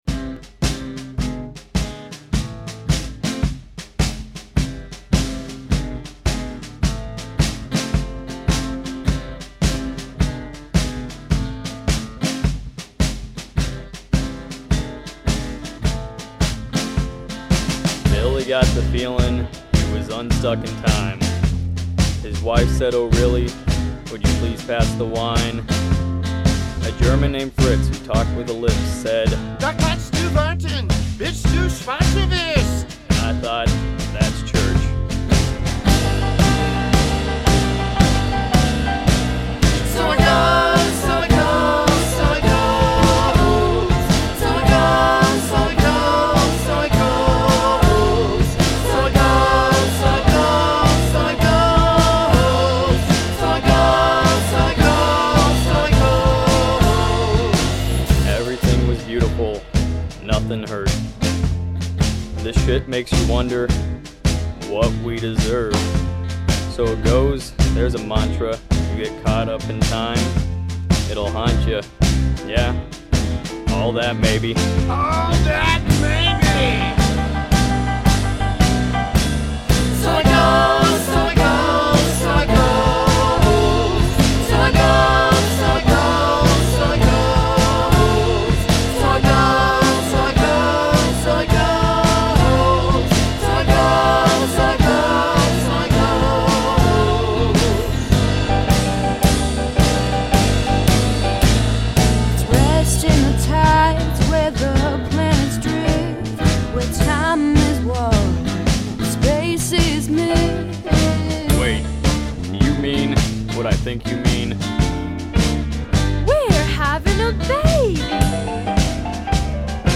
I’ve hosted several successful creative writing classes, and a creators’ salon in which we explored Kurt Vonnegut’s Slaughterhouse Five and then wrote and recorded an original song inspired by the novel.